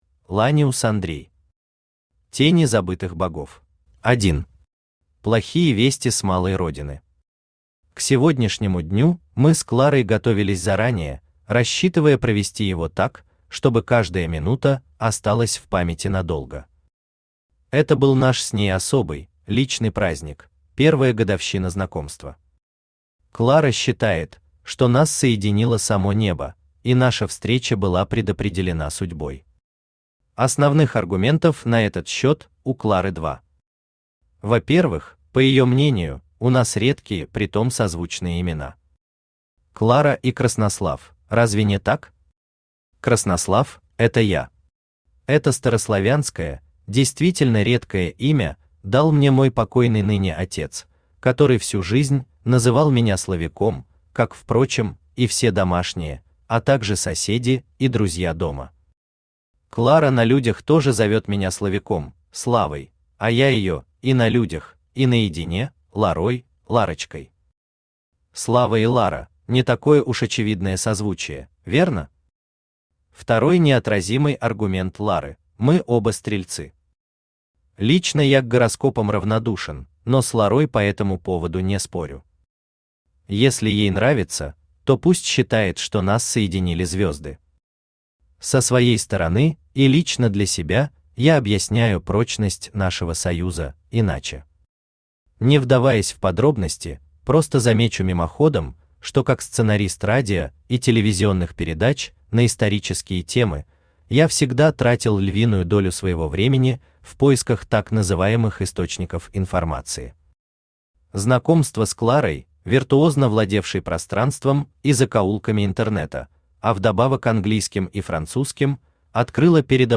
Аудиокнига Тени забытых богов | Библиотека аудиокниг
Aудиокнига Тени забытых богов Автор Ланиус Андрей Читает аудиокнигу Авточтец ЛитРес.